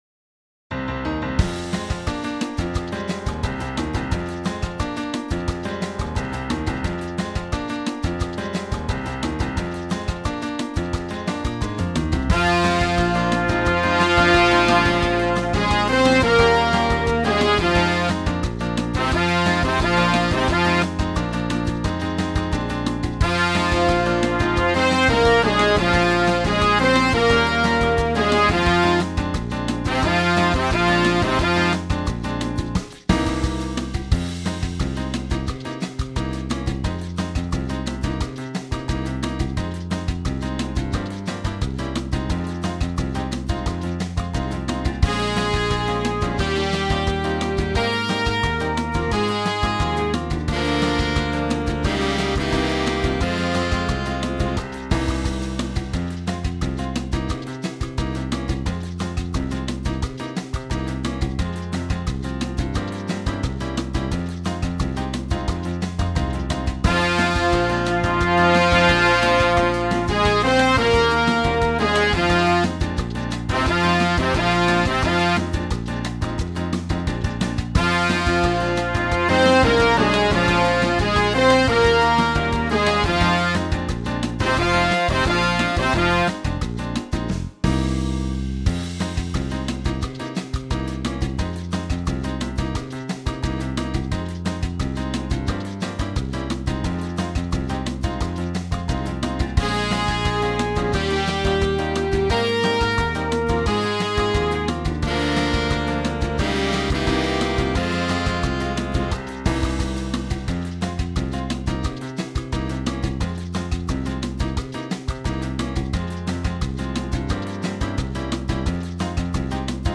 Samba in F minor.
Solo for Alto Sax.